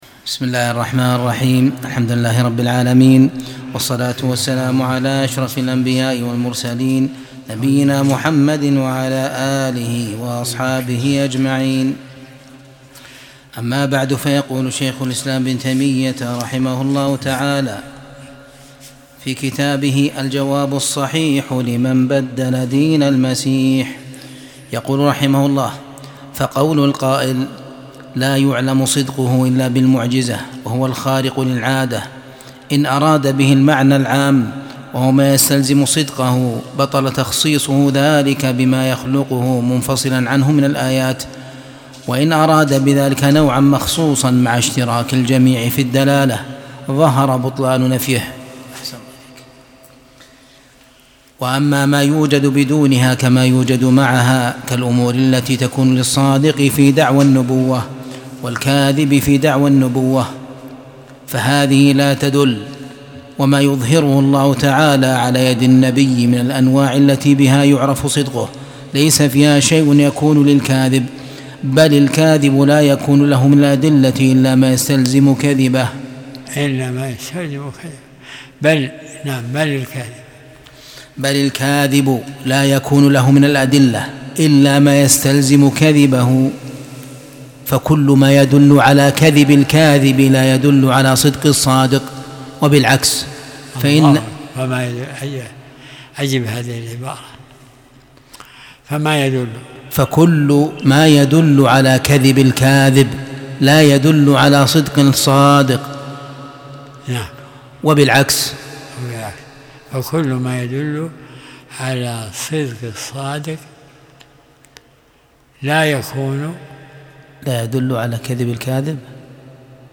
درس الأحد 47